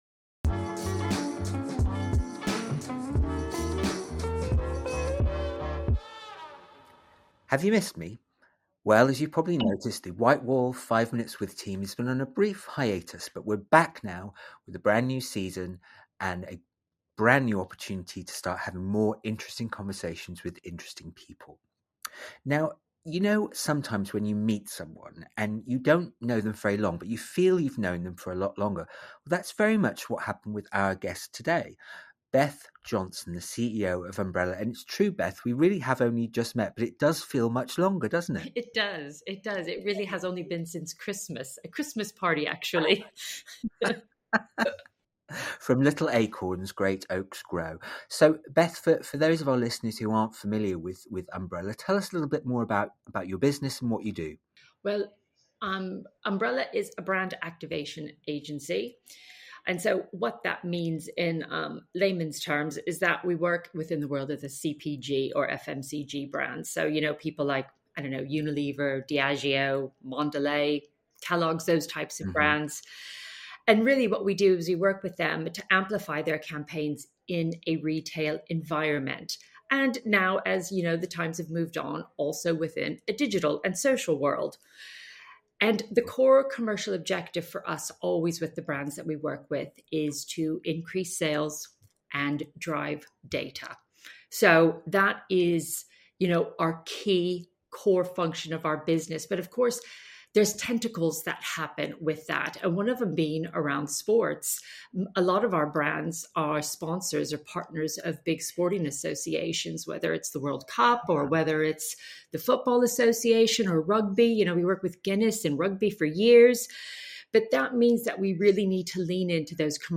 Join our Chief Creative Officer as he is joined by various guests for a 5-minute chat to talk about various subjects.